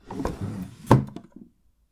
桐タンス引出し閉１
cl_chest_drawer1.mp3